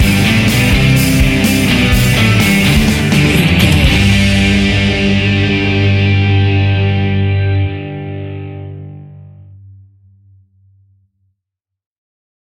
Ionian/Major
D
melancholic
energetic
uplifting
electric guitar
bass guitar
drums
pop rock
indie pop